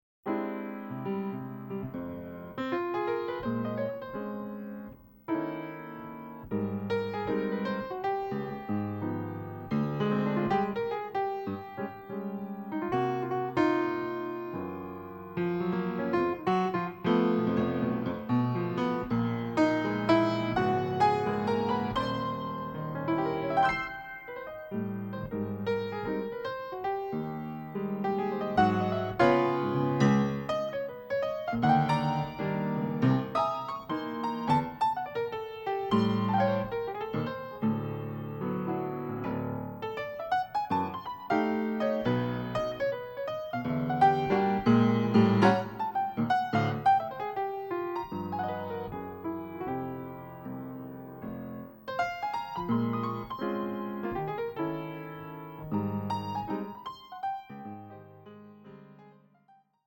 Genre: Jazz.
piano
bass
drums and percussion
tenor and soprano saxophones